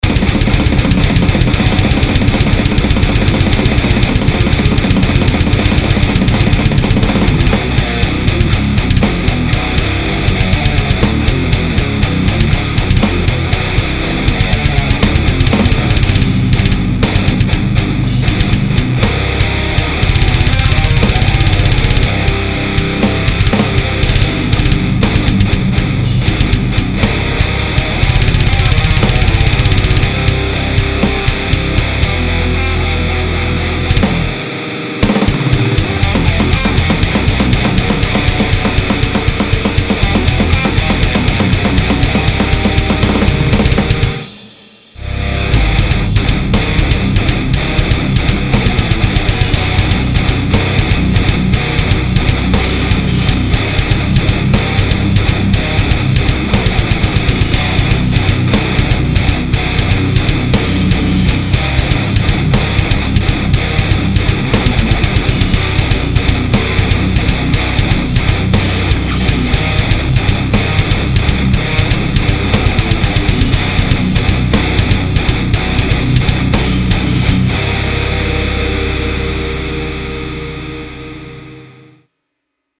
�������� �� Deathcore. 3 �����